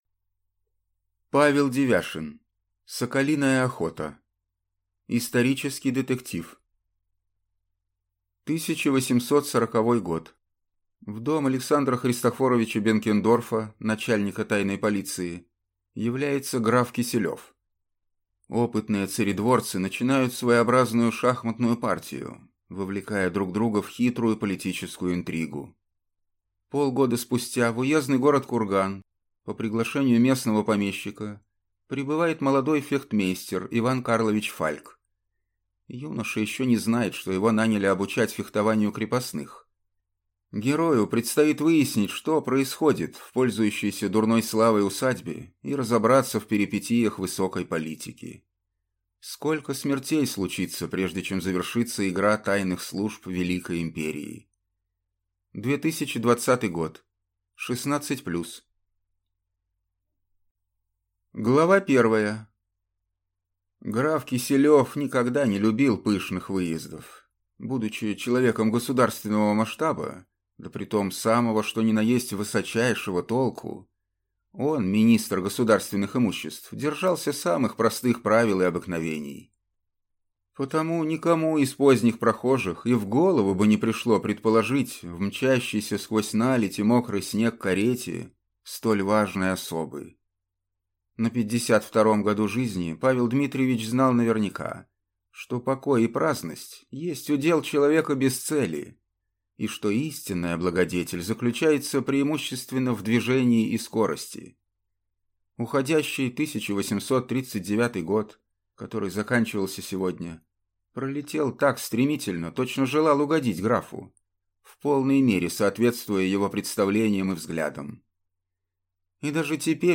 Аудиокнига Соколиная охота | Библиотека аудиокниг